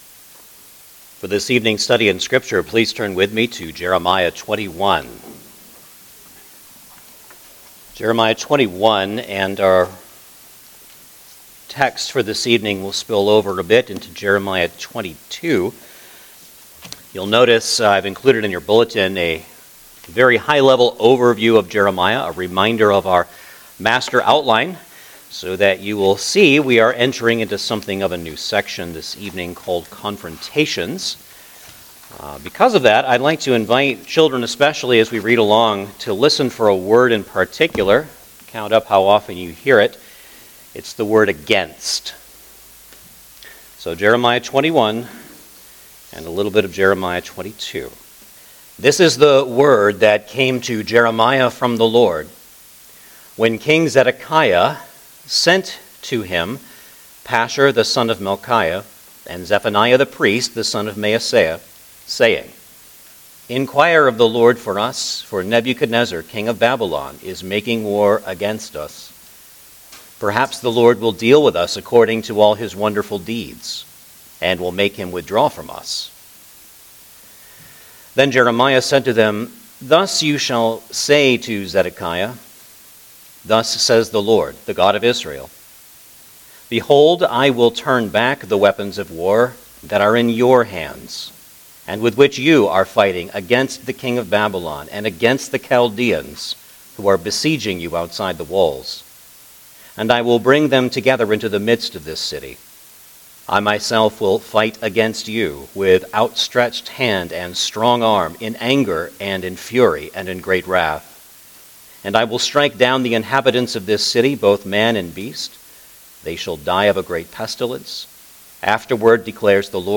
Jeremiah Passage: Jeremiah 21:1 - 22:9 Service Type: Sunday Evening Service Download the order of worship here .